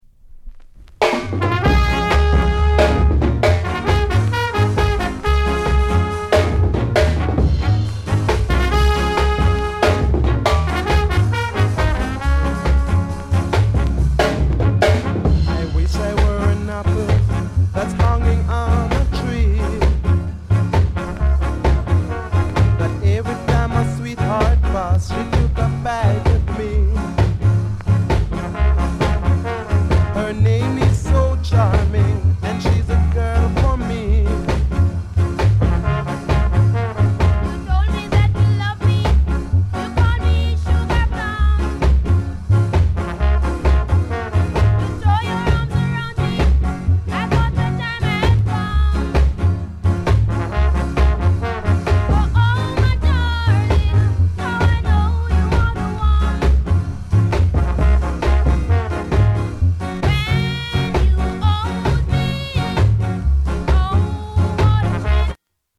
SOUND CONDITION A SIDE EX-